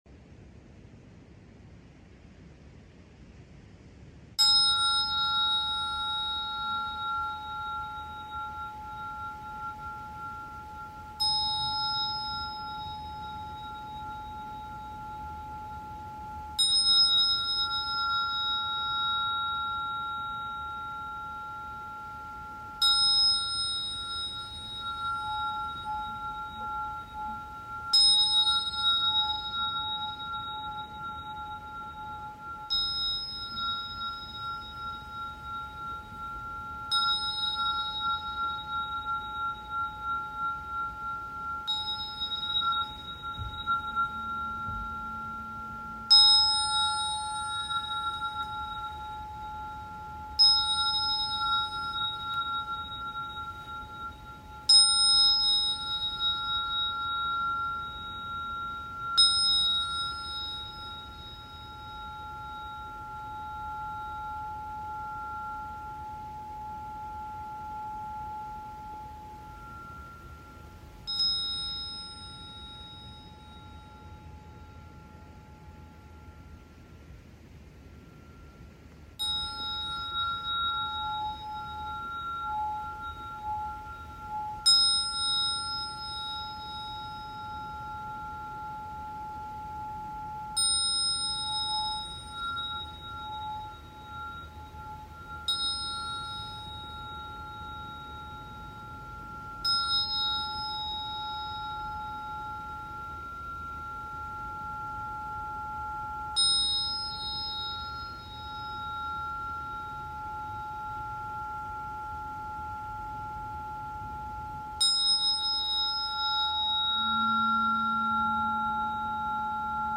This Tuning Forks Video adjust sound effects free download